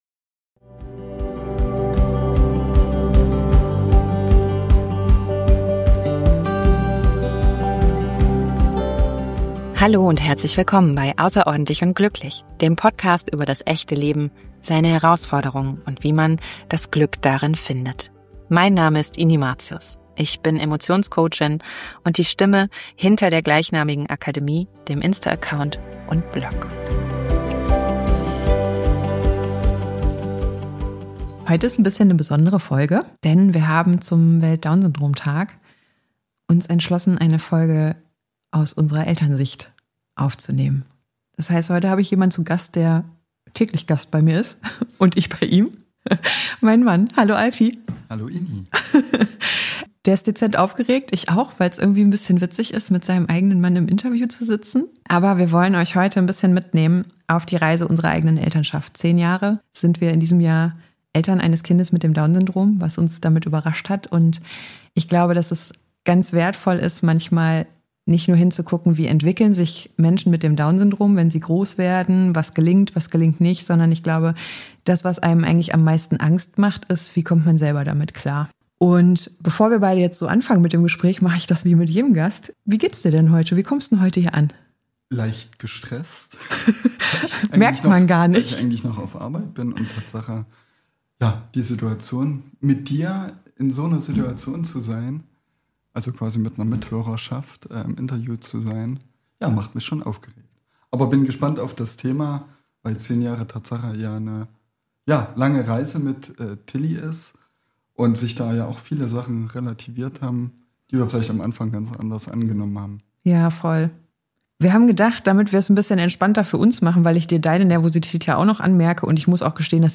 #68 - Zusammenhalt - Interview einer pflegenden Ehe ~ außerordentlich & glücklich Podcast